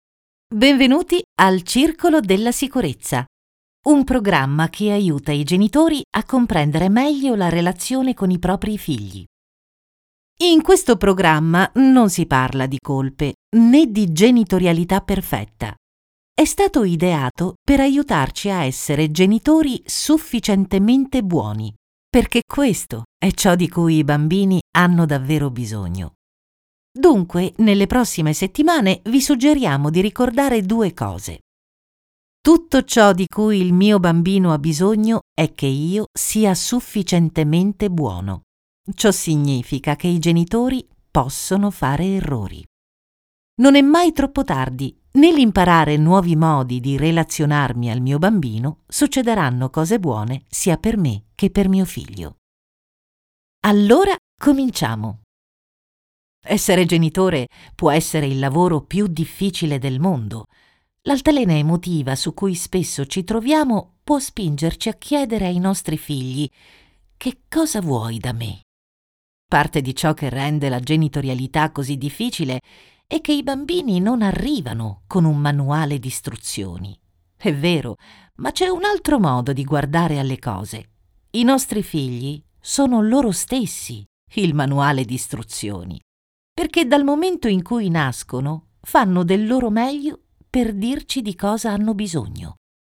Natürlich, Vielseitig, Zuverlässig, Erwachsene, Sanft
Erklärvideo